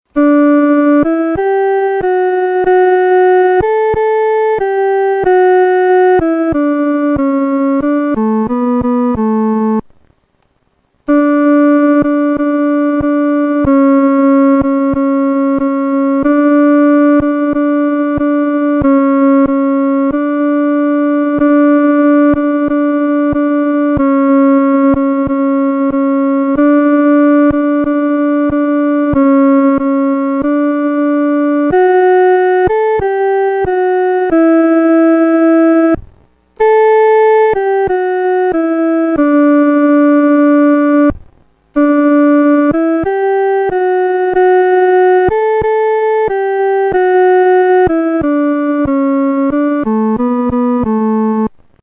独奏（第二声）